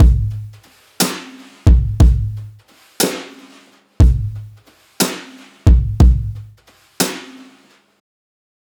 Unison Jazz - 5 - 120bpm.wav